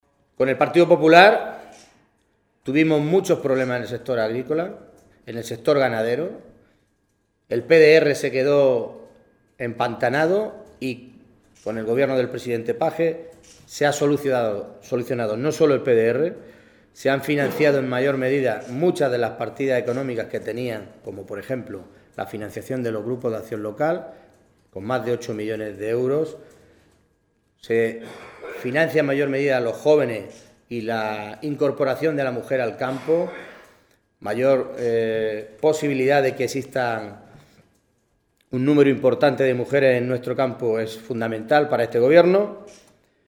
Sáez, que realizó estas declaraciones momentos antes del inicio de la comisión de Agricultura de las Cortes regionales donde se abordaba este asunto, indicó que esta cifra viene a poner de manifiesto la importancia que da el actual gobierno a que los agricultores y ganaderos puedan contar con una cobertura de sus cosechas y explotaciones.
Cortes de audio de la rueda de prensa